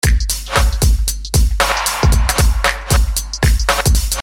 鼓循环
Tag: technodrum